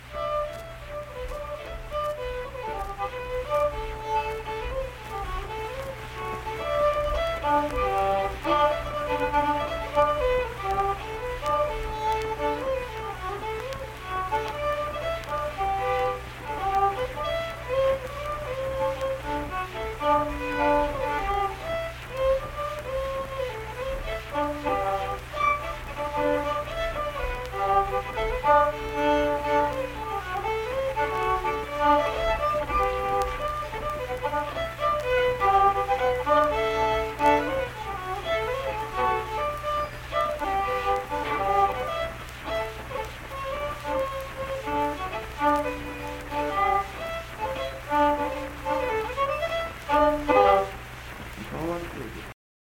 Unaccompanied fiddle music
Instrumental Music
Fiddle